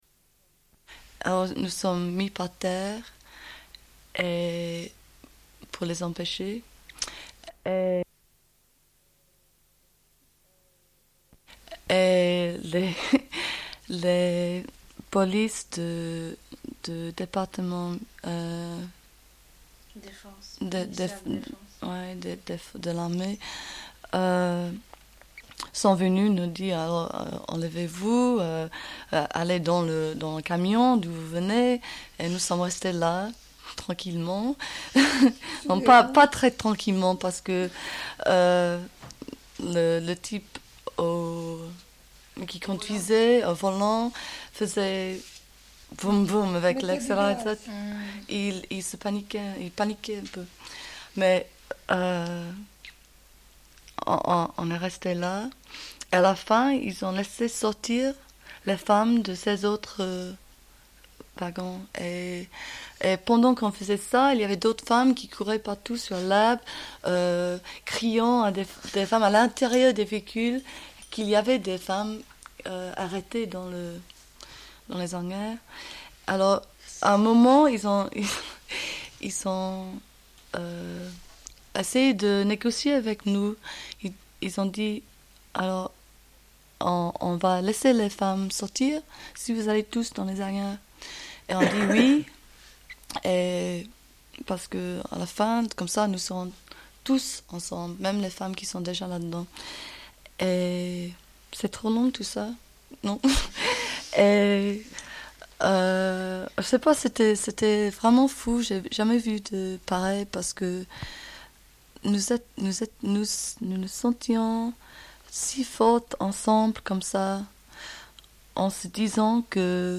Une cassette audio, face B47:23
Suite de l'émission du 6 mars 1984, interview avec des femmes de Greenham Common (GB) et Comiso (IT), présentes à Genève à l'occasion de l'arrivée des marcheur·euse·s de la paix arrivants de Berlin.